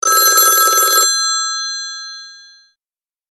Доисторический...)) Звонок старого телефона